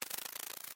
Звуки божьей коровки
Шум взлета божьей коровки